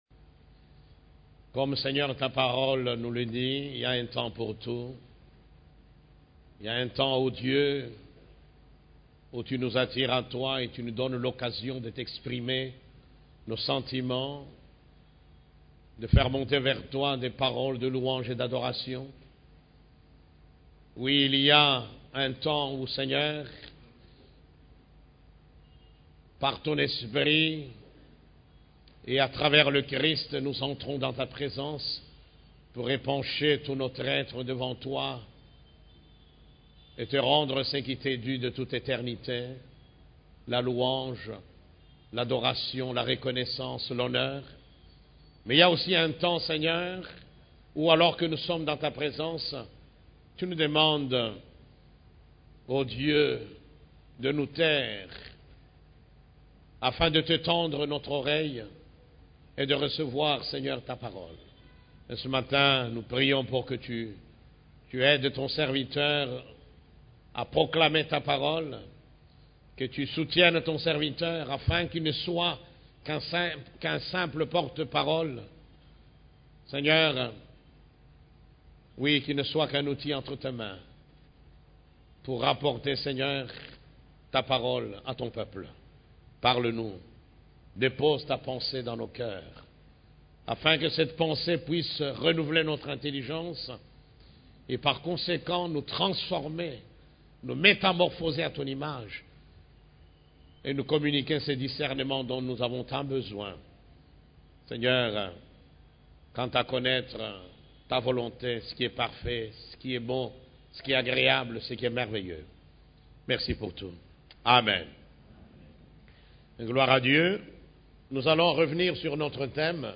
CEF la Borne, Culte du Dimanche, Invitation au voyage sur les chemins de la prière (3)